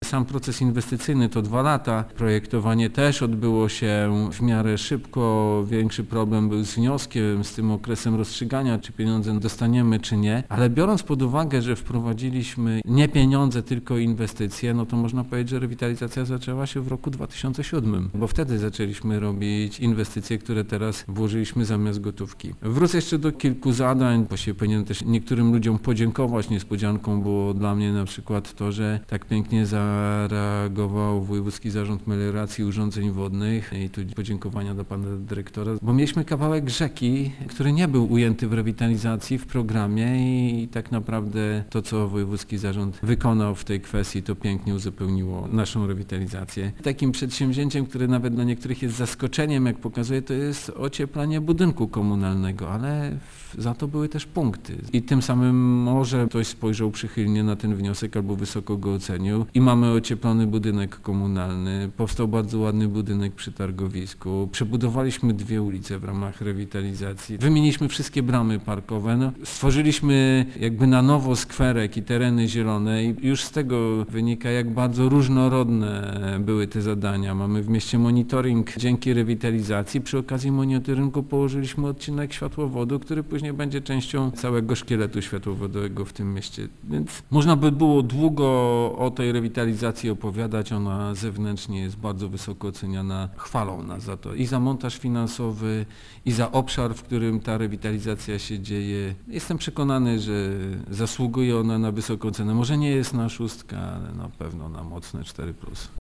- Nie chcieliśmy nadmiernie zadłużać gminy, dlatego wczytując się w zasady naboru wniosków o środki z Regionalnego Programu Operacyjnego Województwa Lubelskiego, znaleźliśmy zapisy, świadczące o tym, że wkładem własnym mogą być wcześniej zrealizowane zadania - tłumaczy burmistrz Nałęczowa.